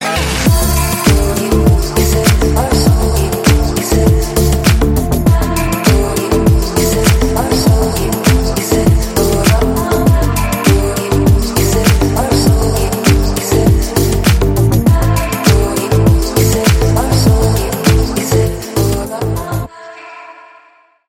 атмосферные
спокойные